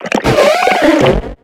Cri de Coudlangue dans Pokémon X et Y.